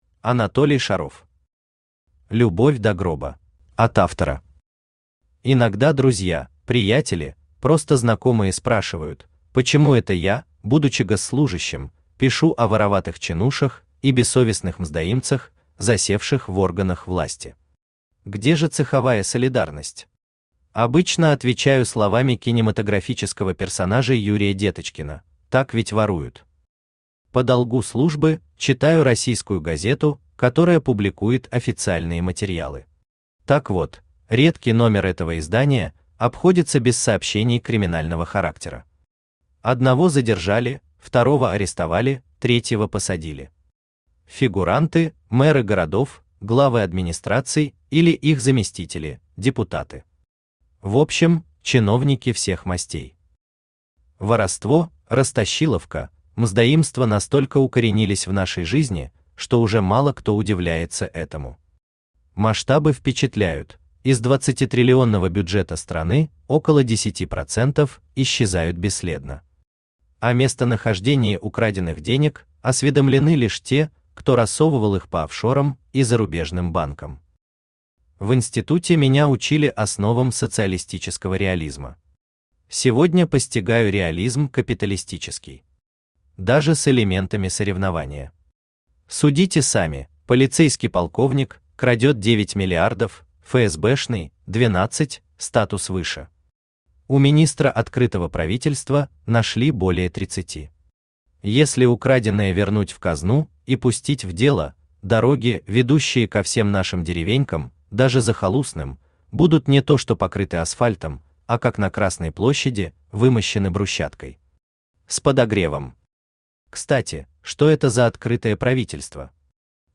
Аудиокнига Любовь до гроба | Библиотека аудиокниг
Aудиокнига Любовь до гроба Автор Анатолий Петрович Шаров Читает аудиокнигу Авточтец ЛитРес.